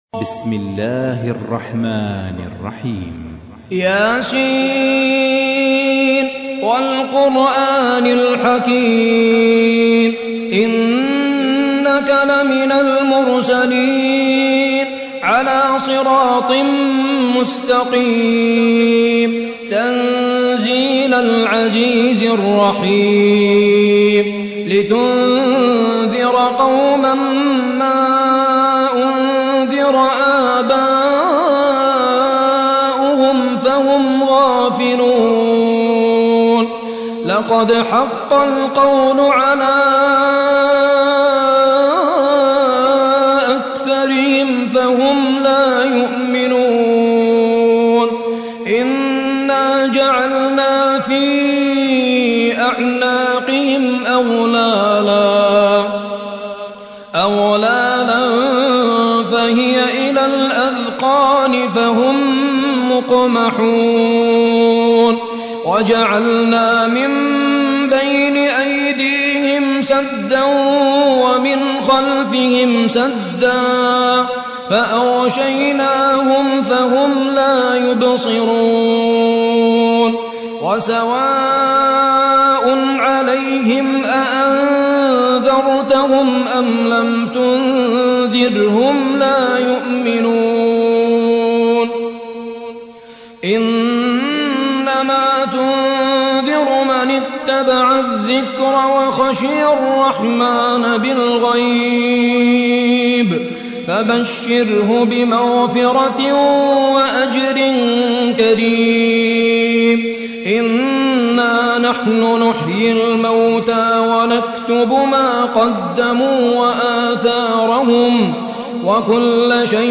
36. سورة يس / القارئ